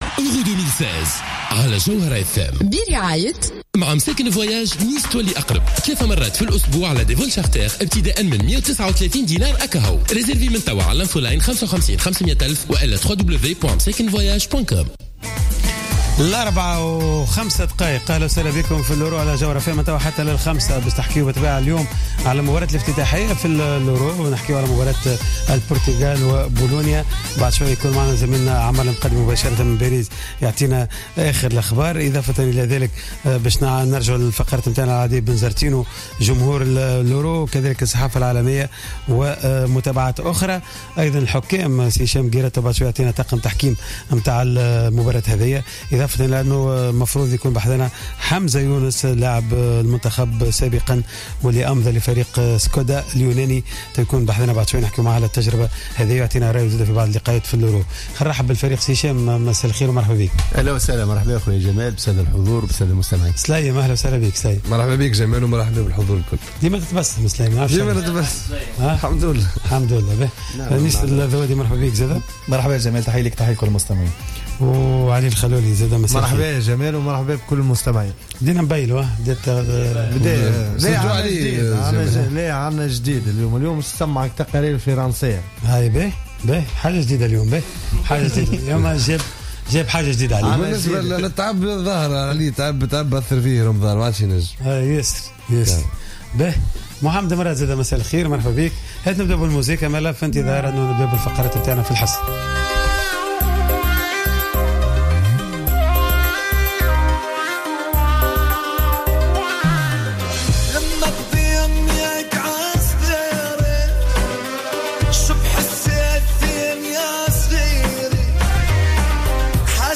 مباشرة من باريس.